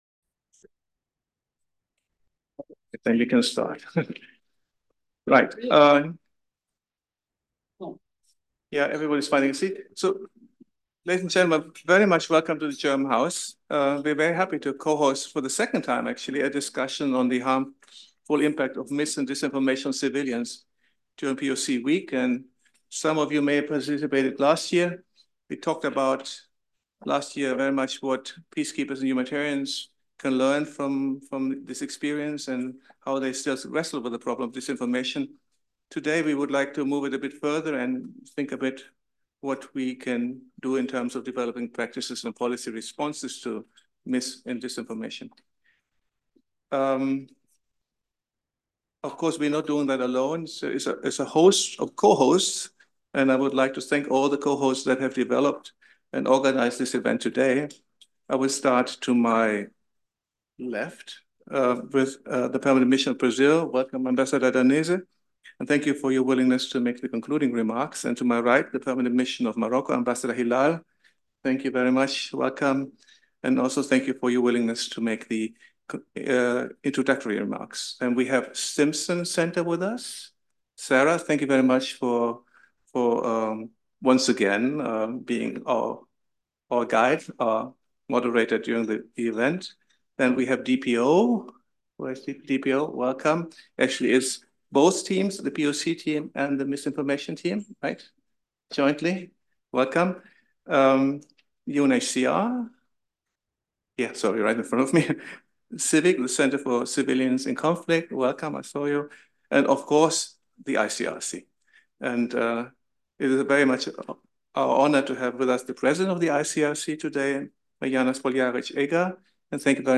This dialogue was organized in a hybrid format as part of “POC Week” on the margins of the annual U